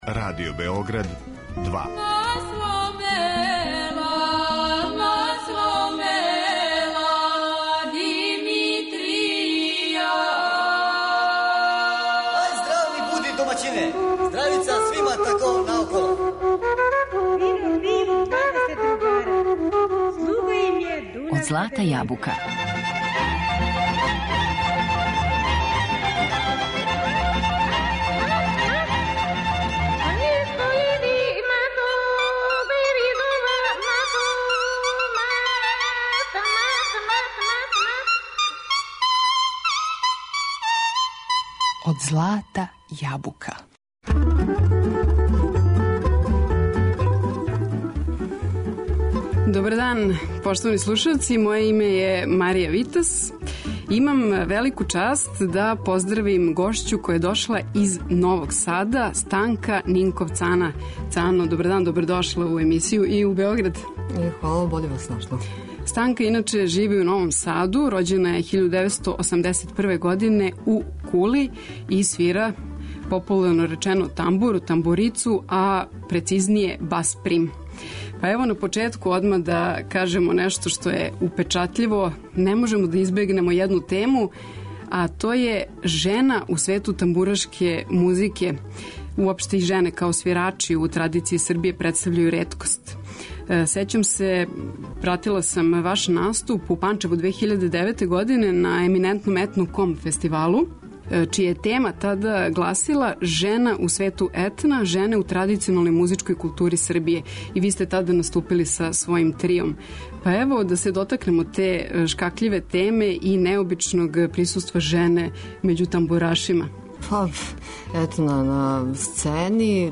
Тамбурашица